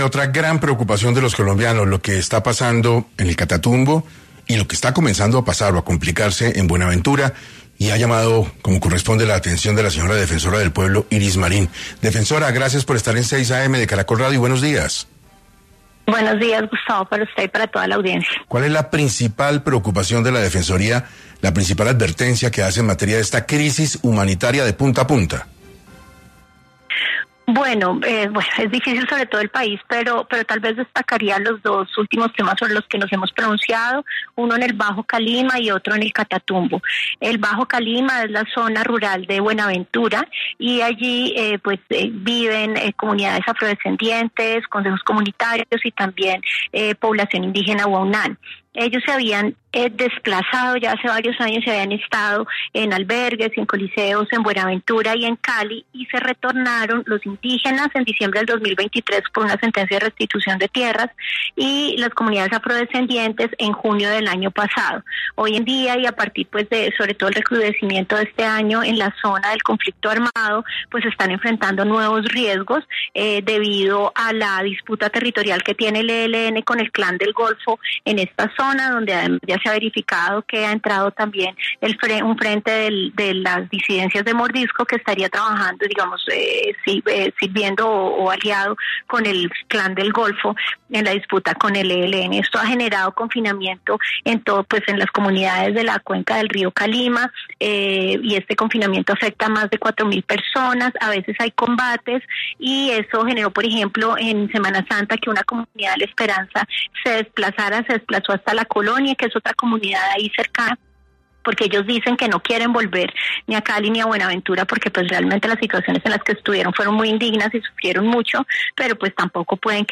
Ante la gravedad del asunto, La defensora del Pueblo, Iris Marín, habló para 6AM sobre lo que está sucediendo e hizo un fuerte llamado al Gobierno Nacional para mitigar la situación.